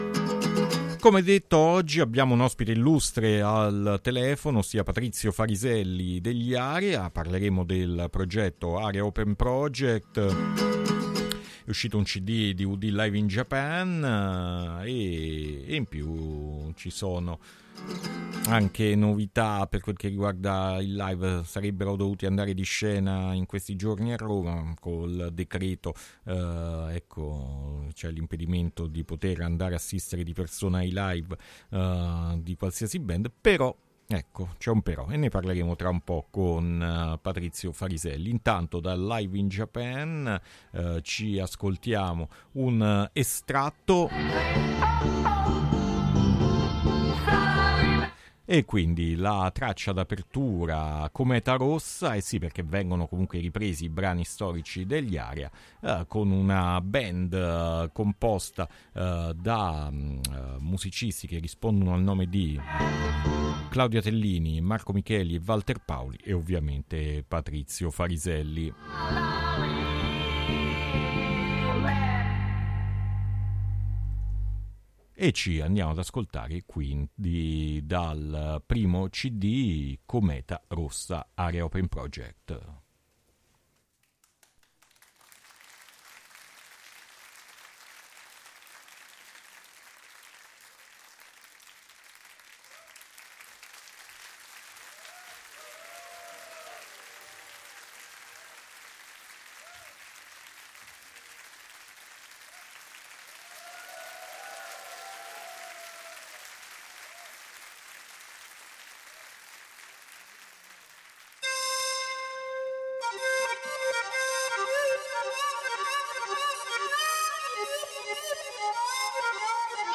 Intervista Patrizio Fariselli Area Open Project | Radio Città Aperta